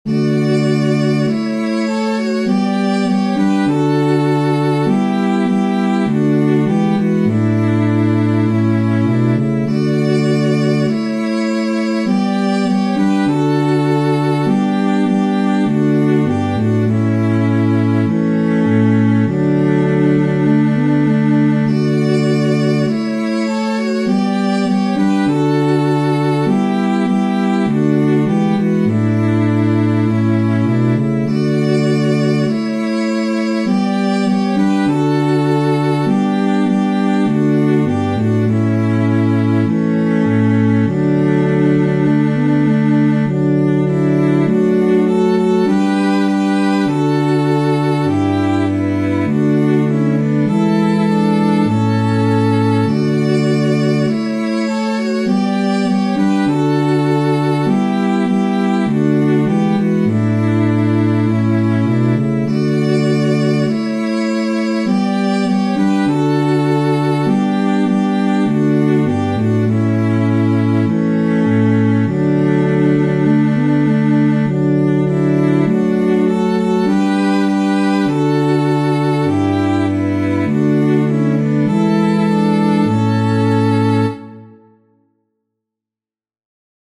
Chants d’Acclamations.